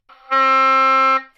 Oboe single notes » Oboe C4
标签： C4 goodsounds singlenote multisample neumannU87 oboe
声道立体声